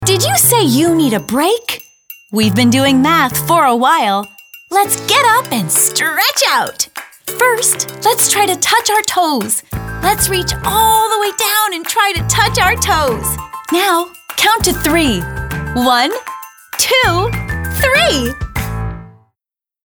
accented English, animated, cartoon, character, confident, cool, girl-next-door, high-energy, humorous, kooky, perky, upbeat